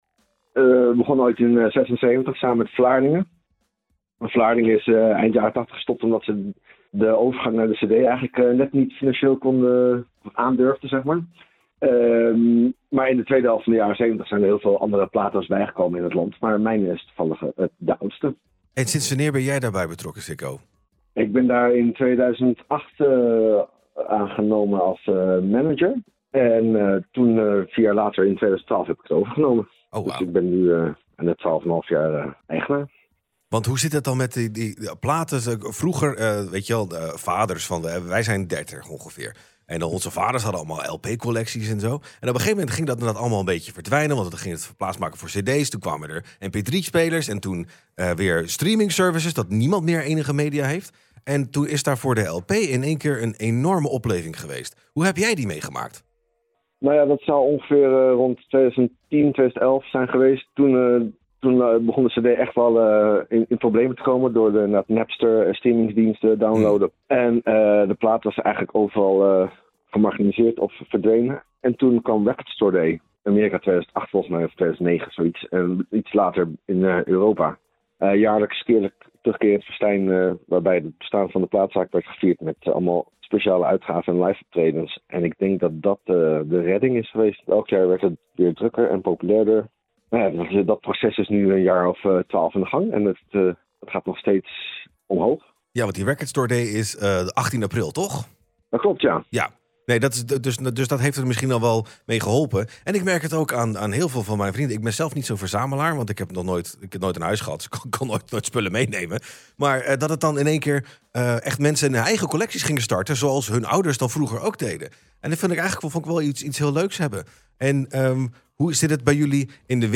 Interview Leiden Maatschappij jarig Jubileum muziek platen Vinyl winkel